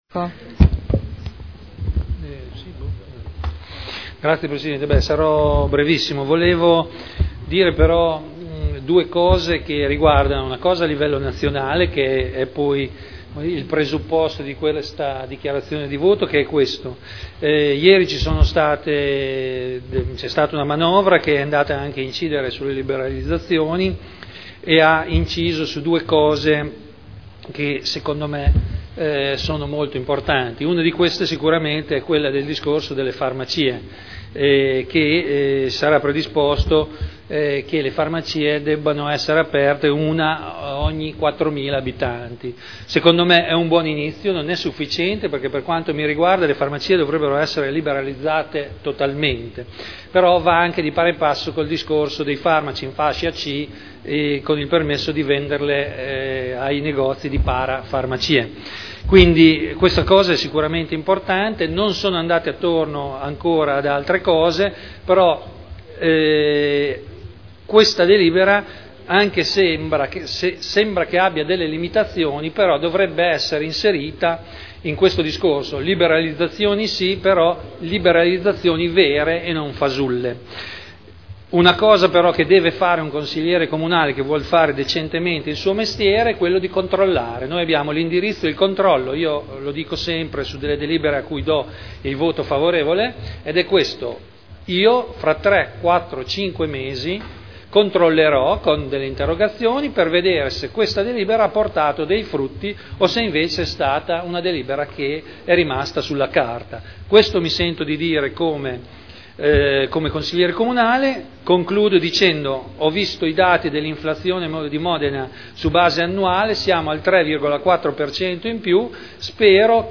Dichiarazione di voto.
Audio Consiglio Comunale